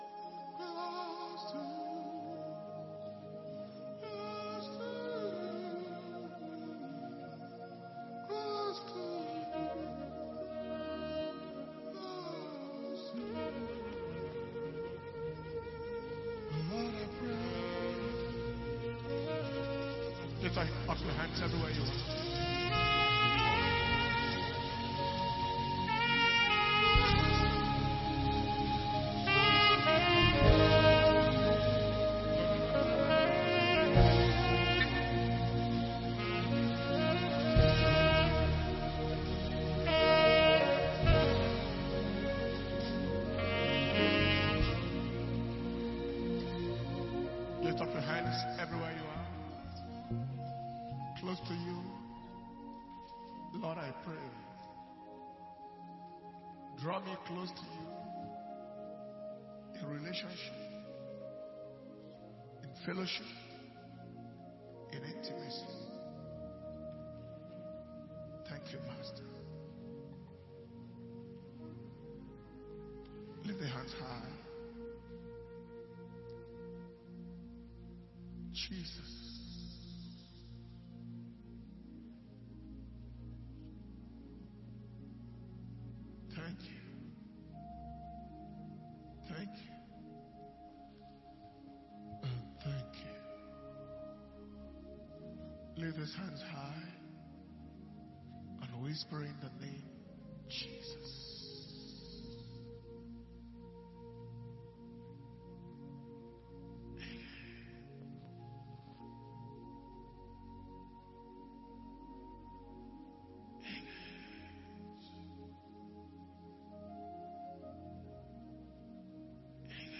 Power Communion Service – 2023 The Glory Is Risen Fast – Day 17 – Wednesday, 26th January 2023